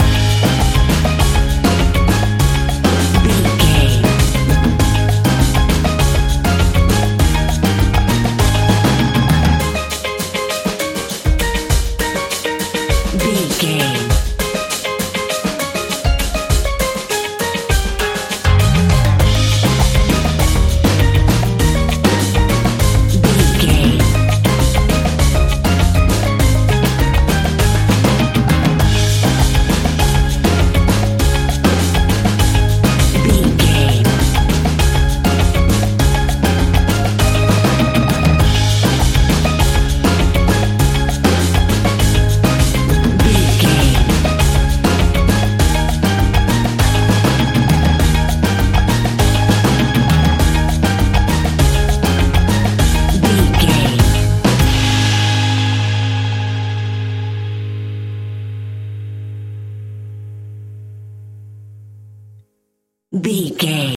Ionian/Major
A♭
steelpan
drums
brass
guitar